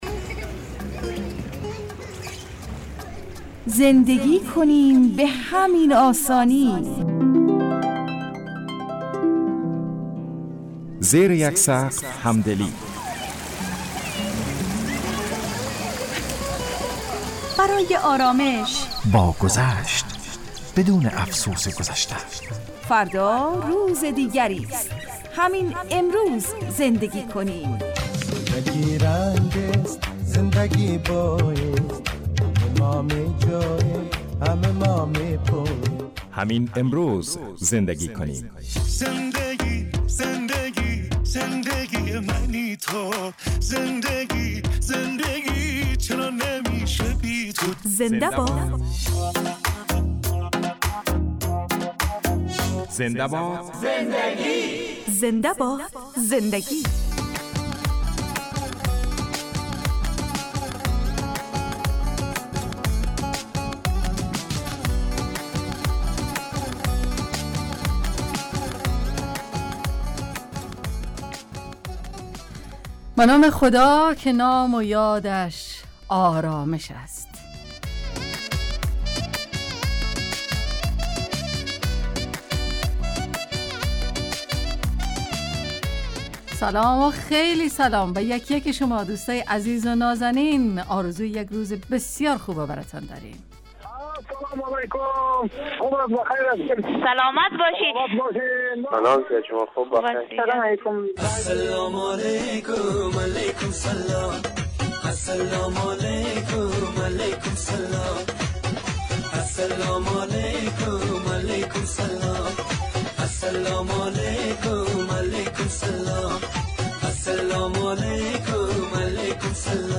گوینده
کارشناس